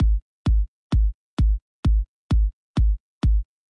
描述：用reaktor lime lite ensemble.
标签： drumloop drummachine 循环